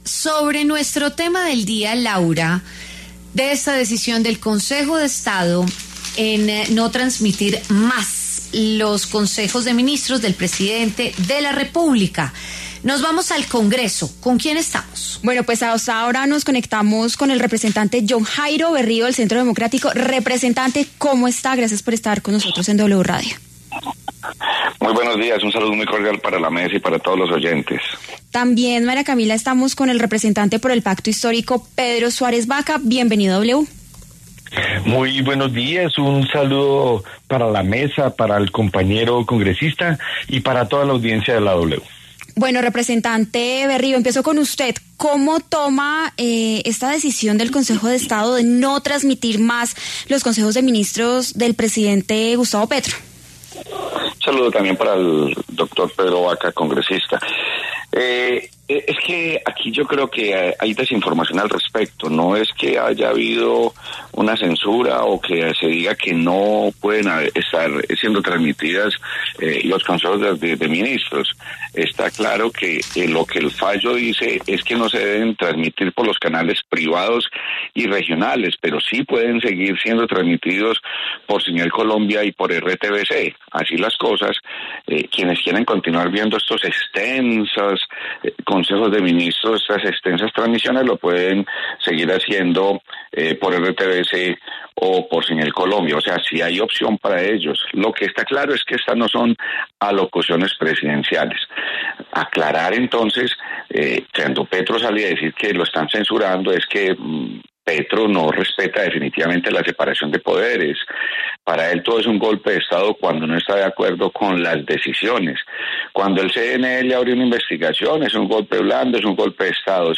Debate: ¿qué dice el Congreso tras la decisión sobre los consejos de ministros?
Los congresistas Jhon Jairo Berrío y Pedro Suárez hablaron en W Fin de Semana sobre el fallo que ordena a Presidencia no transmitir los consejos de ministros a través de canales privados, locales y regionales.